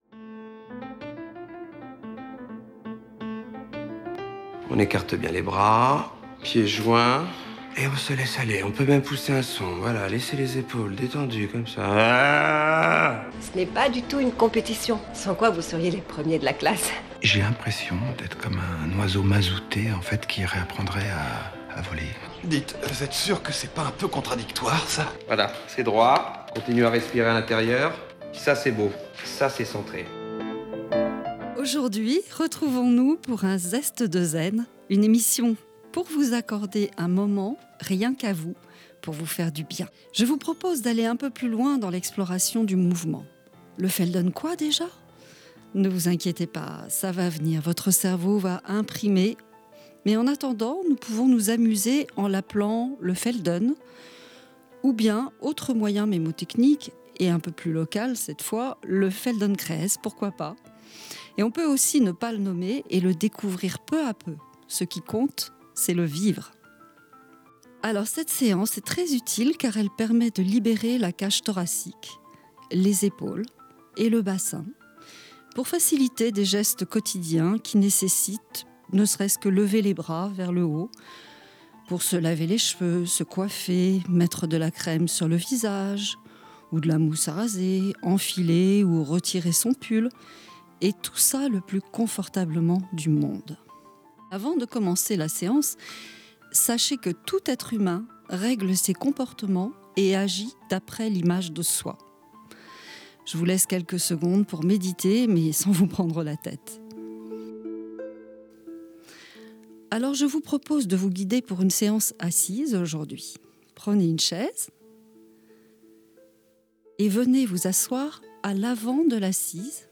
Laissez-vous guider, sans effort et profitez de l'instant et de cette découverte de votre corps, de vos postures et de vos gestes. Ce mois-ci, on lèvera les bras avec aisance et légèreté.